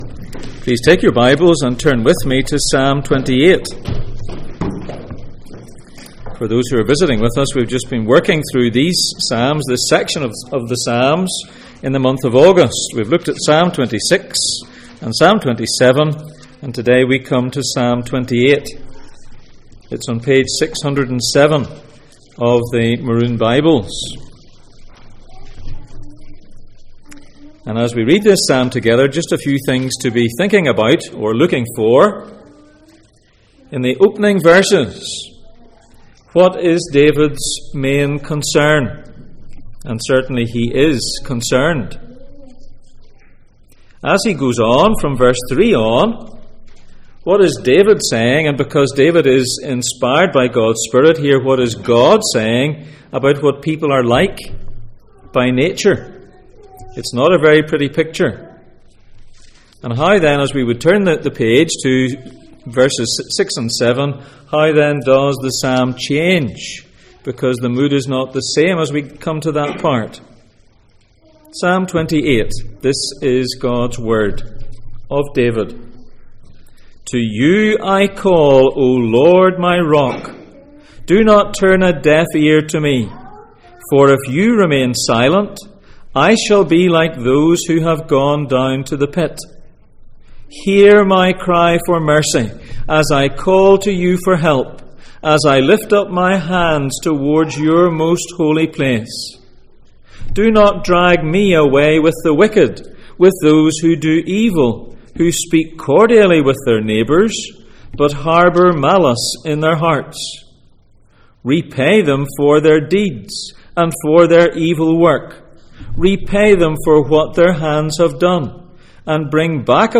Psalms Passage: Psalm 28:1-9 Service Type: Sunday Morning %todo_render% « What do you do with recurring fear?